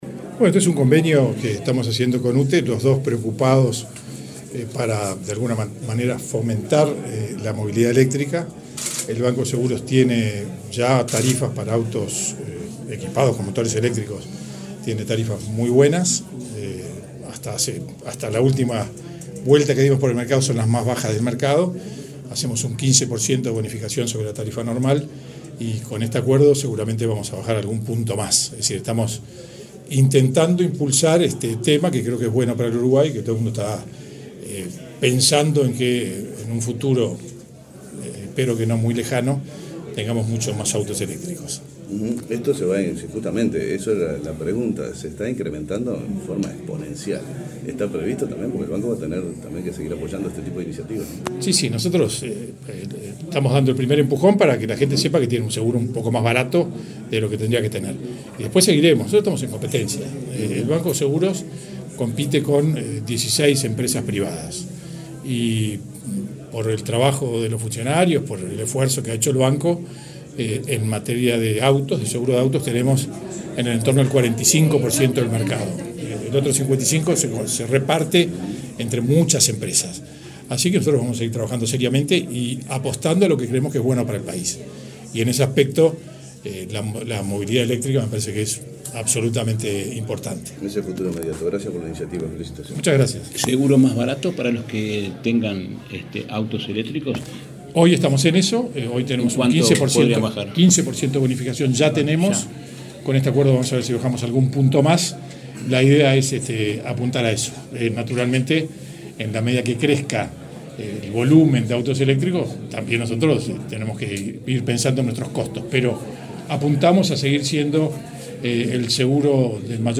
Declaraciones del presidente del BSE, José Amorín Batlle
Declaraciones del presidente del BSE, José Amorín Batlle 19/12/2022 Compartir Facebook X Copiar enlace WhatsApp LinkedIn Este lunes 19, la UTE y el Banco de Seguros del Estado (BSE) firmaron un acuerdo de complementación comercial. Luego el presidente del organismo asegurador, José Amorín Batlle, dialogó con la prensa.